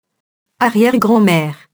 arrière-grand-mère [arjɛrgrɑ̃mɛr] nom féminin (pluriel arrière-grands-mères)